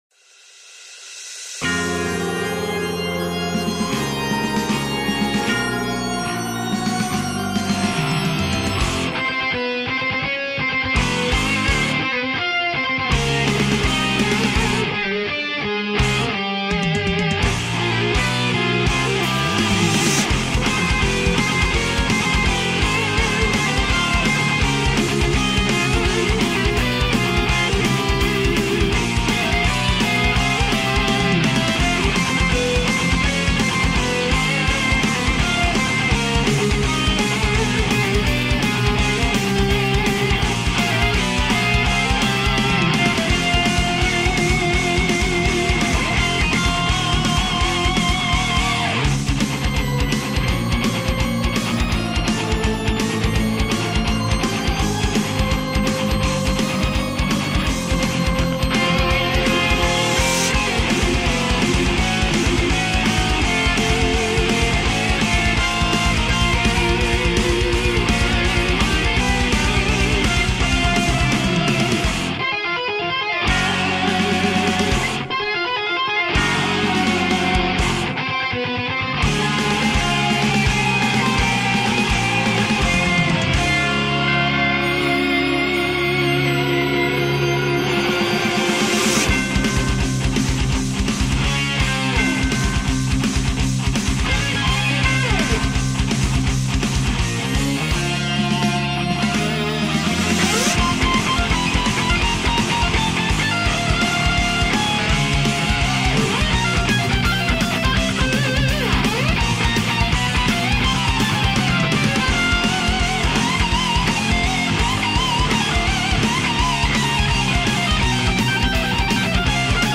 Symphonic Metal Cover